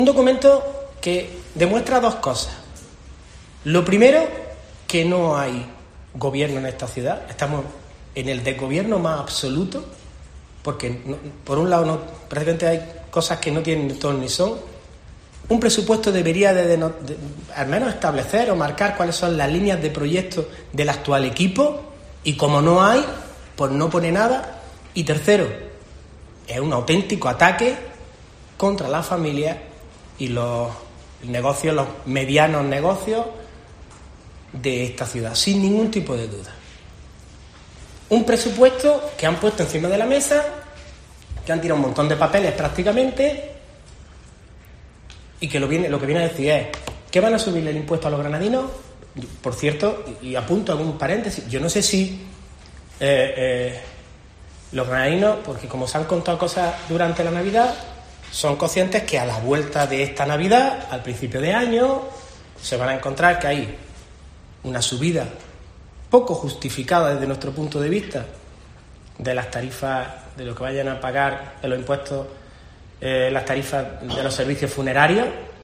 Paco Cuenca, portavoz del PSOE en el Ayuntamiento